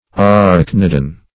arachnidan - definition of arachnidan - synonyms, pronunciation, spelling from Free Dictionary Search Result for " arachnidan" : The Collaborative International Dictionary of English v.0.48: Arachnidan \A*rach"ni*dan\, n. [Gr.